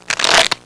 Dealing Cards Sound Effect
Download a high-quality dealing cards sound effect.
dealing-cards.mp3